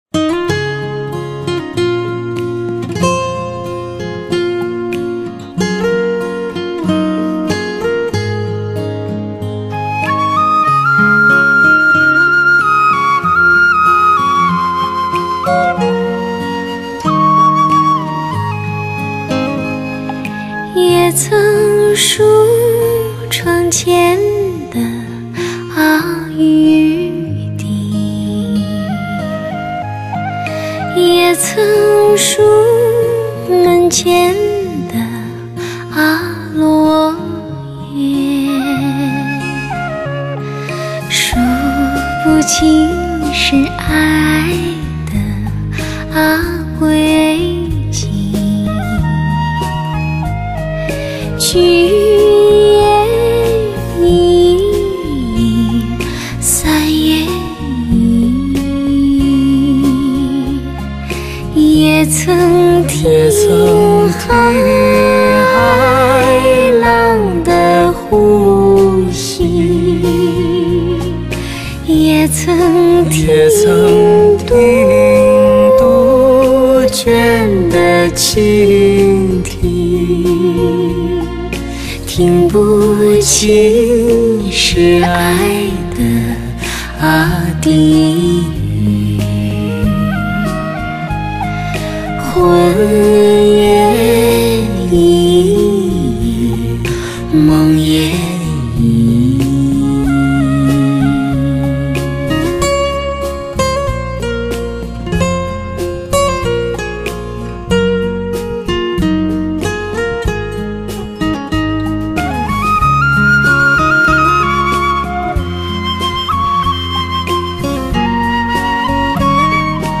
天赋清透甜美的声音，宛如人间的精灵。
我们从这男女对唱的甜美歌声里来感悟人生，感悟生活。